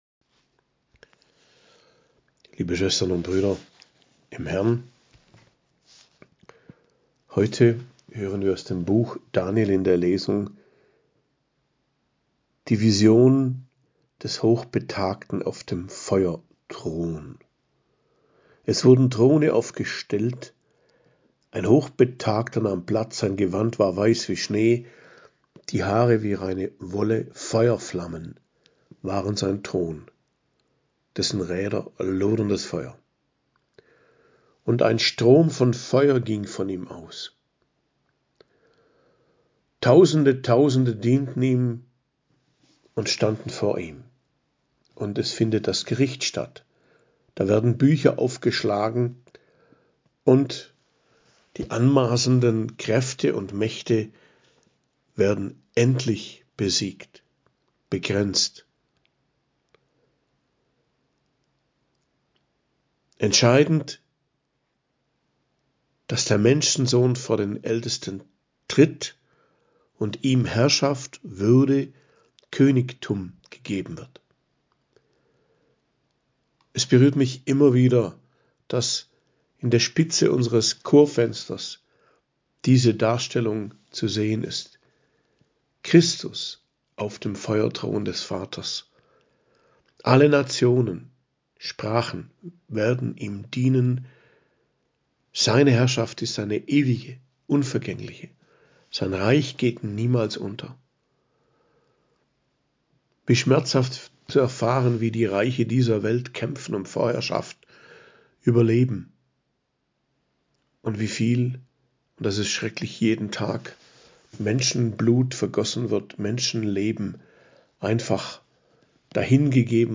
Predigt am Freitag der 34. Woche i.J., 1.12.2023